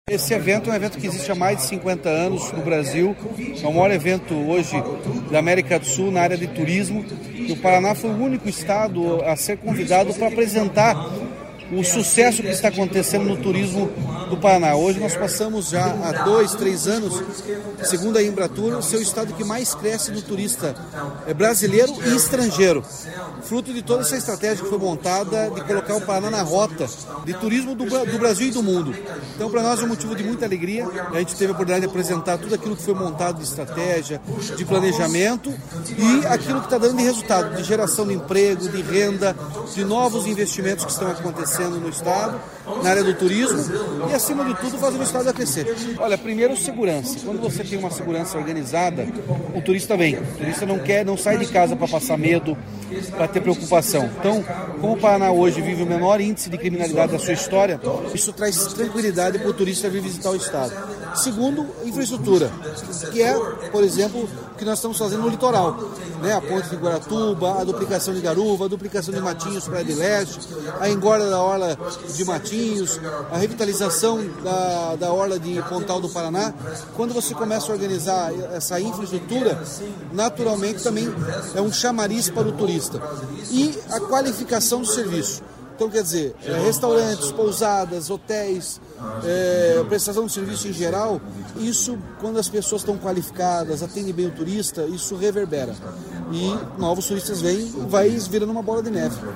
Sonora do governador Ratinho Junior sobre o 23º Fórum PANROTAS 2026